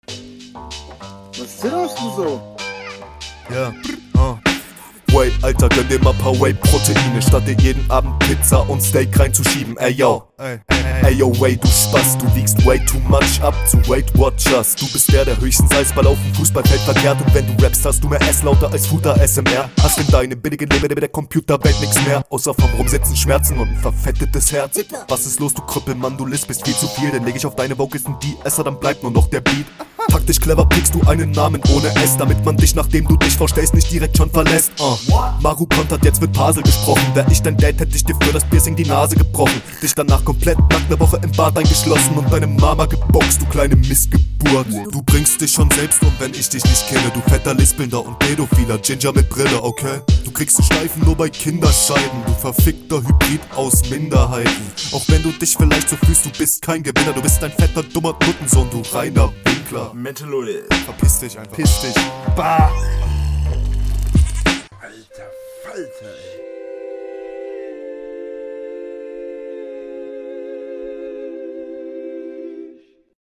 funky.
Schöner Oldschoolbeat, der dir besser steht. Flow klingt sehr nice drauf.
Ja wieder geil durch den Beat geshuffled, passt alles. sitzt alles.